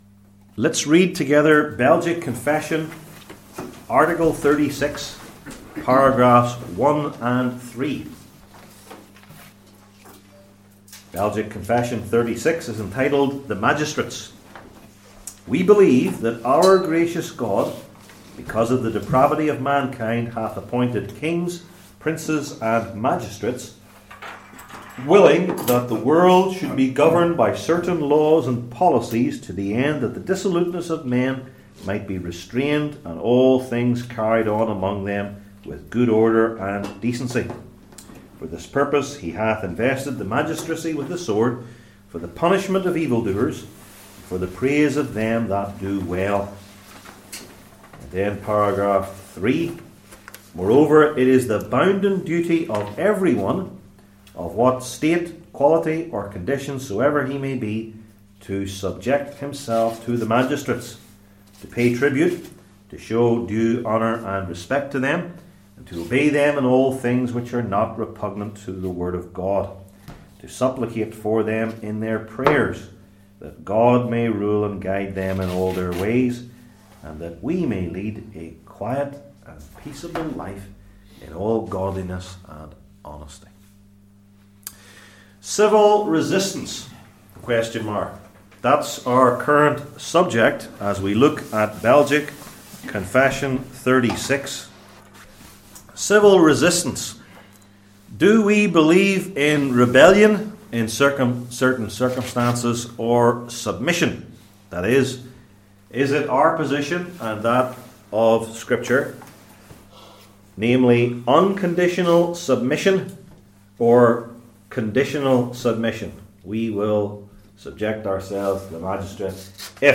Service Type: Belgic Confession Classes